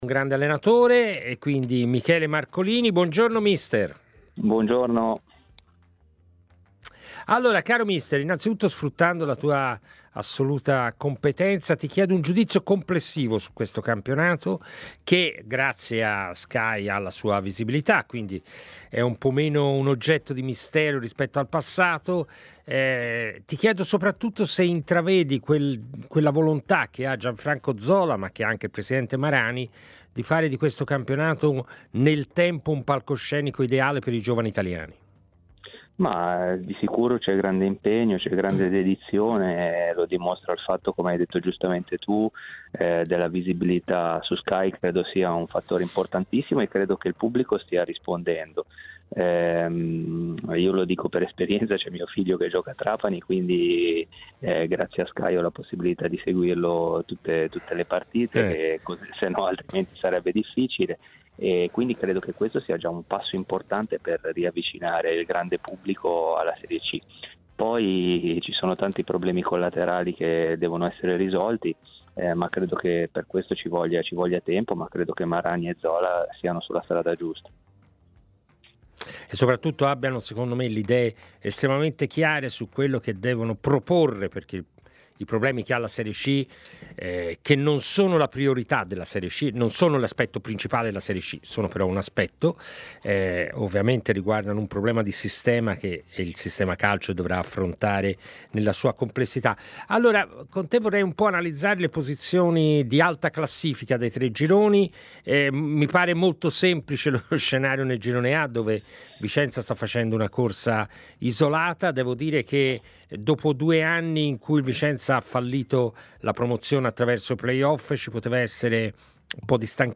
è intervenuto come ospite di 'A Tutta C', trasmissione in onda su TMW Radio e iL61.